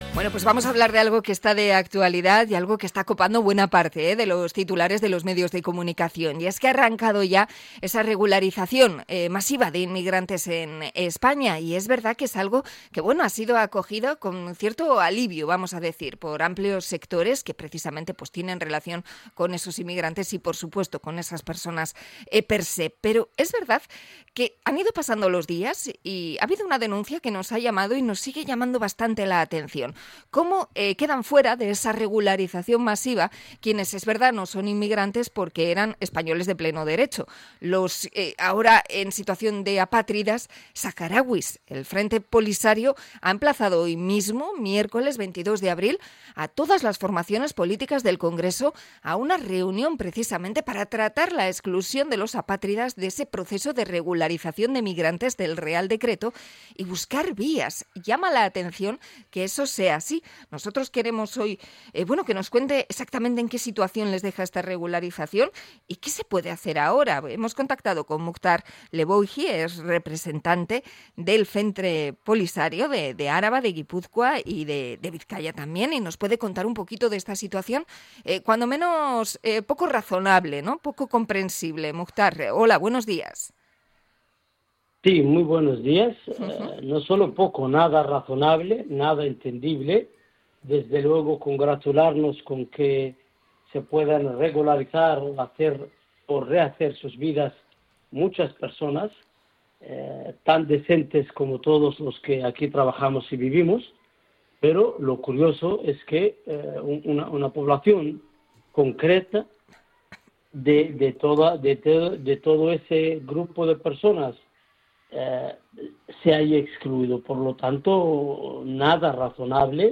Entrevista sobre la exclusión de los saharauis en la regularización del gobierno